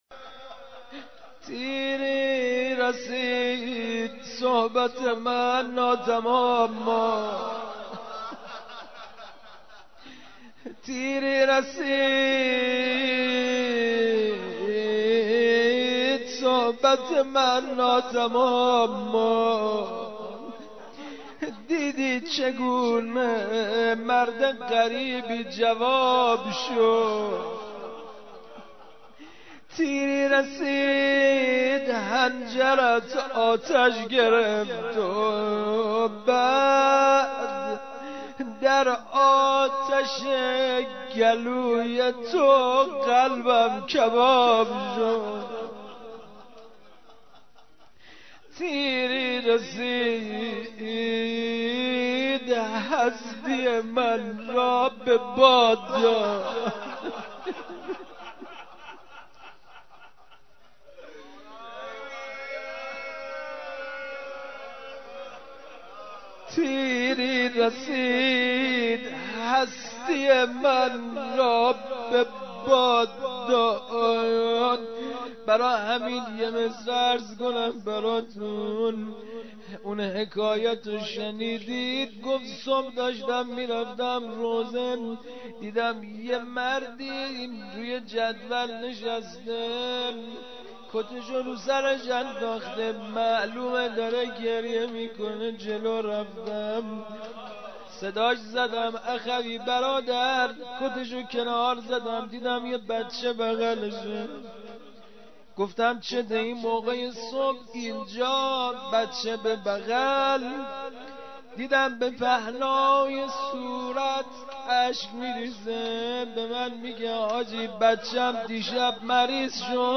جلسه هفتگی؛ دوازدهم صفر 1434؛ روضه حضرت علی اصغر علیه السلام ؛ قسمت چهارم